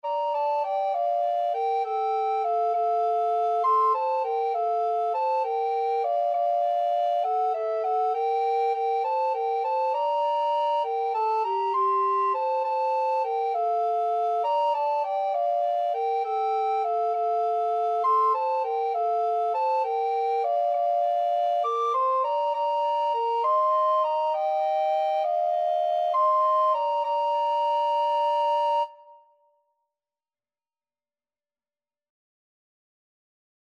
6/8 (View more 6/8 Music)
Classical (View more Classical Alto Recorder Duet Music)